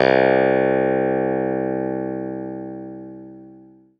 CLAVI1.02.wav